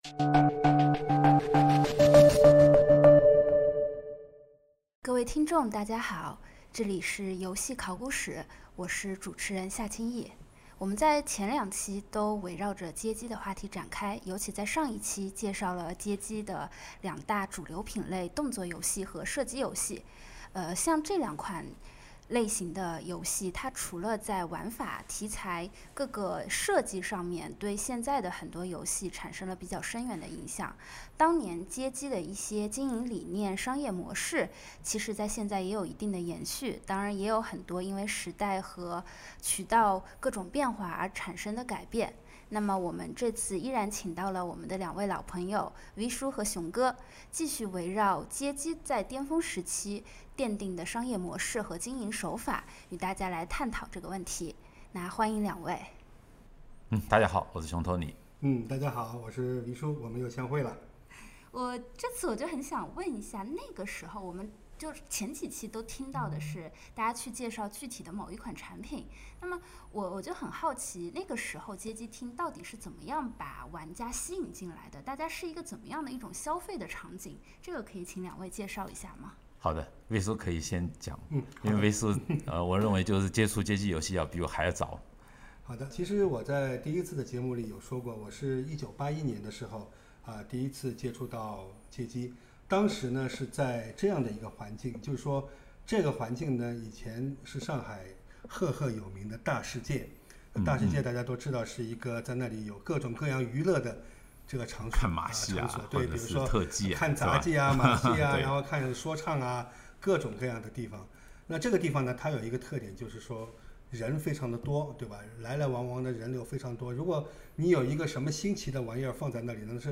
本期「游戏考古史」继续围绕「街机文化」系列，分享街机的商业启示，希望为当下游戏场景化的探索，开启新的视角。 【对话成员】